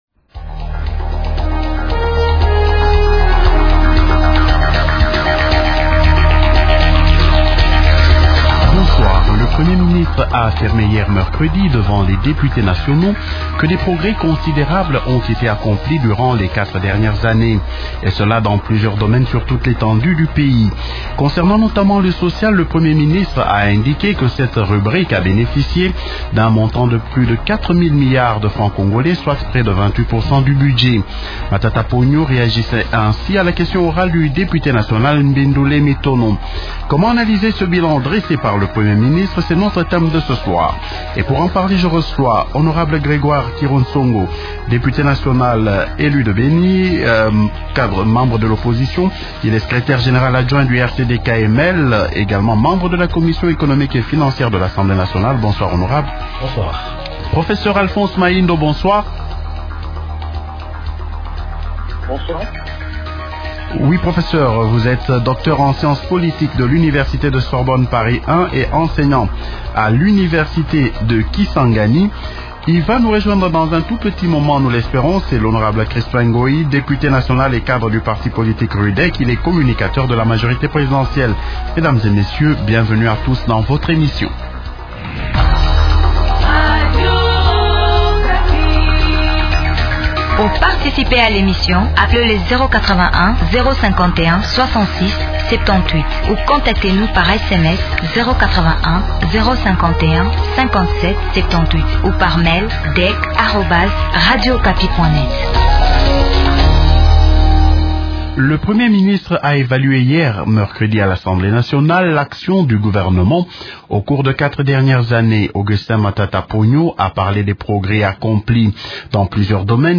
-Comment analyser ce bilan dressé par le Premier ministre ? Invités Patrick Thierry André Kakwata Nguza, député nationale de la majorité présidentielle, élu de Kolwezi et Président du Mouvement des réformateurs libéraux ( Mrl) Grégoire Kiro Tsongo, Député national de l’opposition et secrétaire général adjoint du Rcd/Kml.